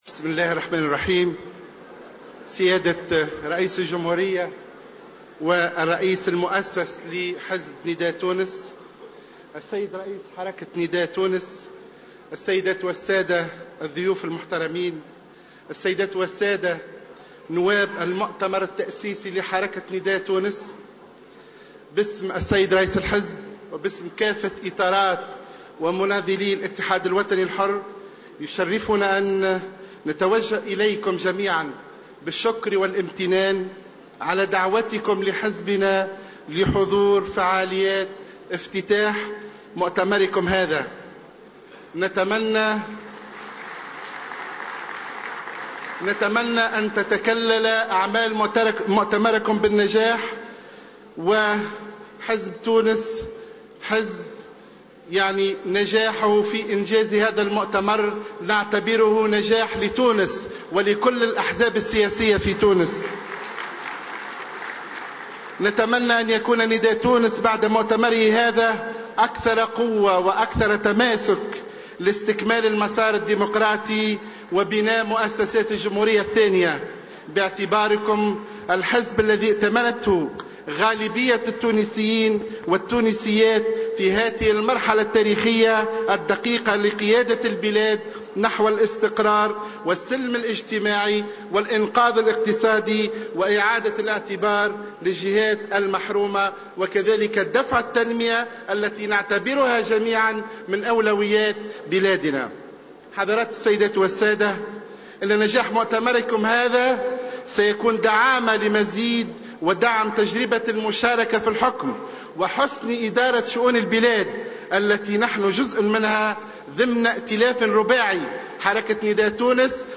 Mohsen Hassan, vice-président du parti de l’UPL, a indiqué lors de son intervention dans le cadre du congrès consensuel de Nidaa Tounes à Sousse, que la réussite de ce congrès représentera un appui pour l’expérience de l’alliance au pouvoir.